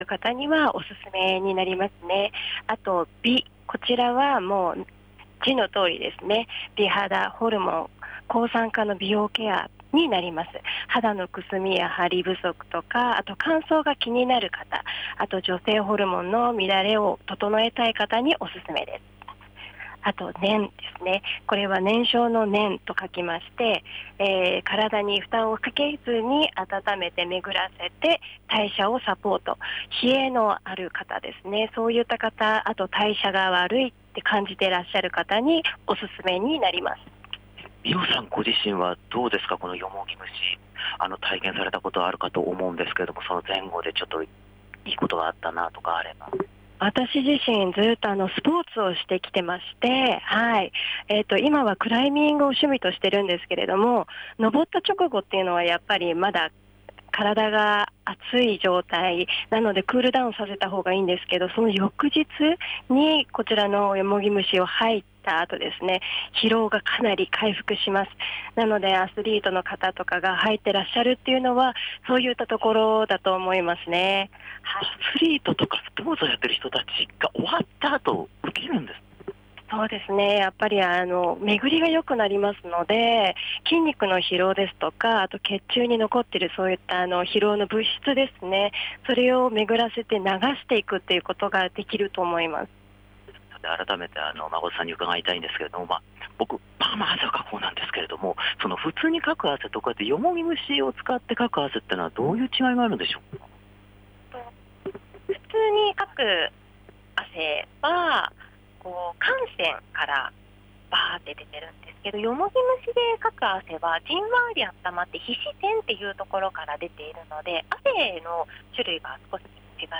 今日から９月ですが、変わらない夏空の下からお届けした街角レポートは、来週9月8日にグランドオープンされる『よもぎ蒸し専門店 癒し日和ぷらす』さんからのレポートです！